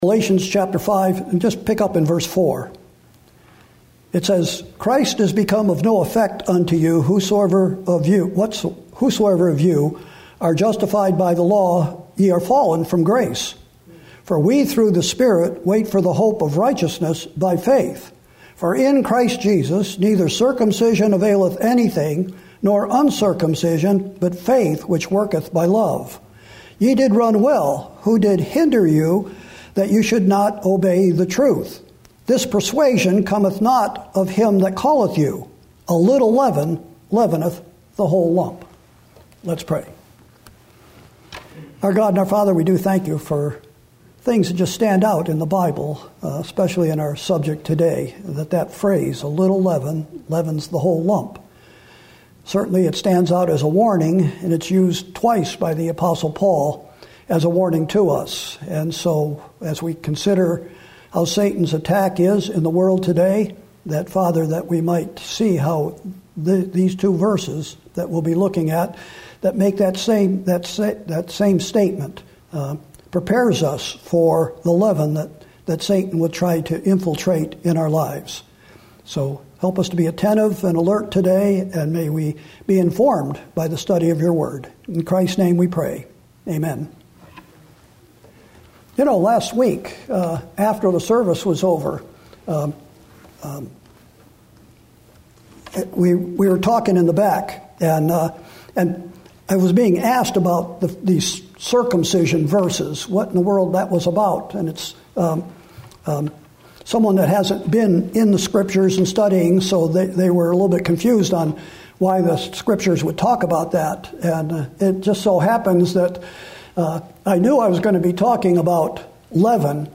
Sermons & Single Studies